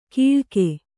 ♪ kīḷke